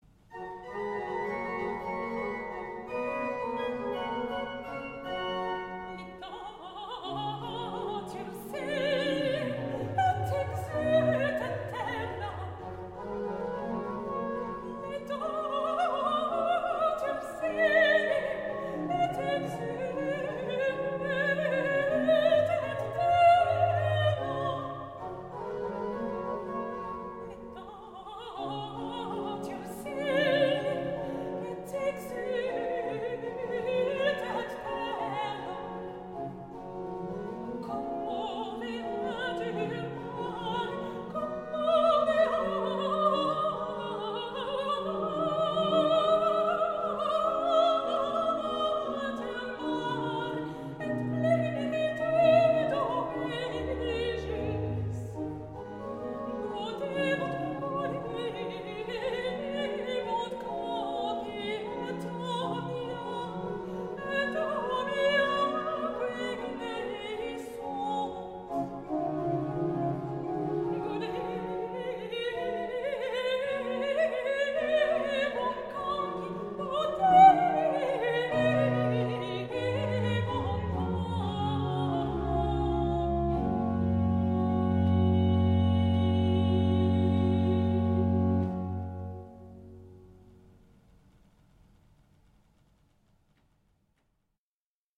Petit motet
~1600 (Baroque)